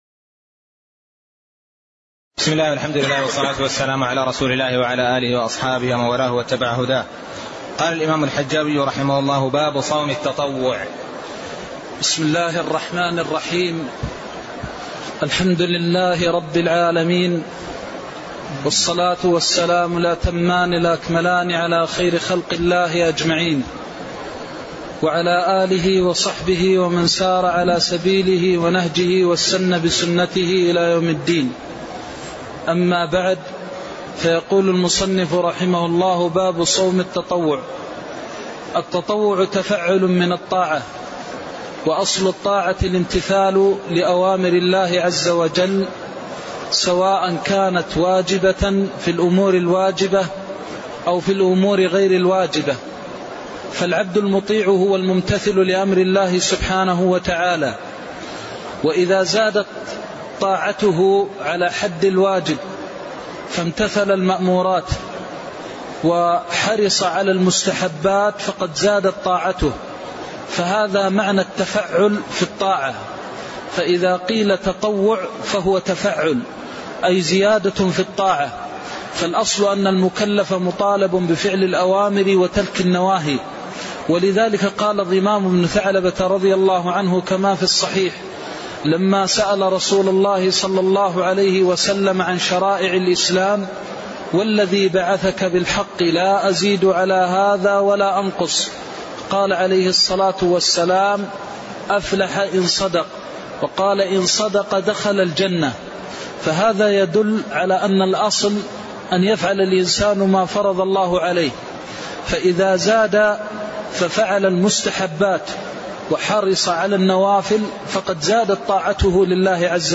تاريخ النشر ٦ رمضان ١٤٣٠ هـ المكان: المسجد النبوي الشيخ